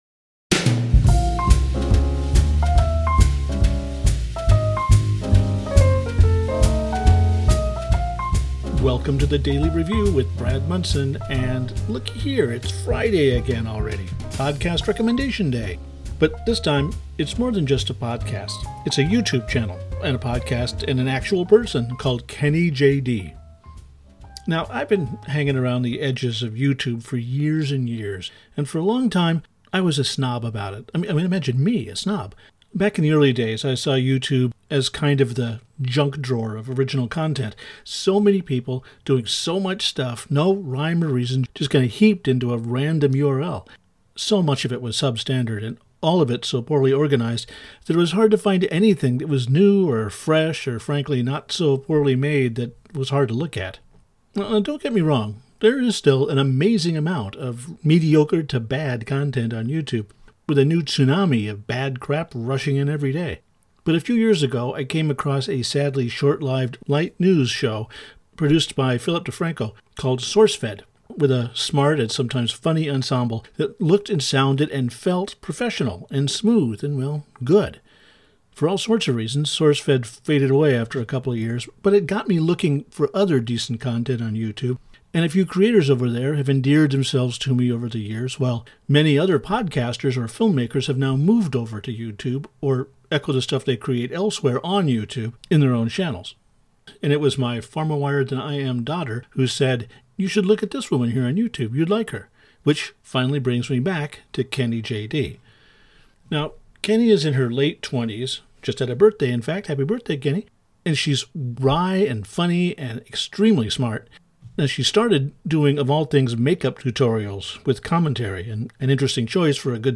And yeah, I snuck in a few bars of one of her songs at the end of the ‘cast there.